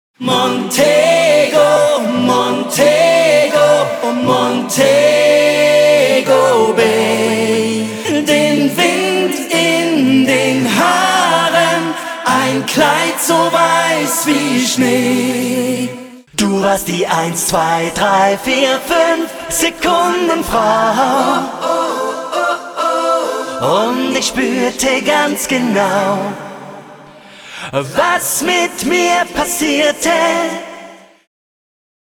Vocal Recording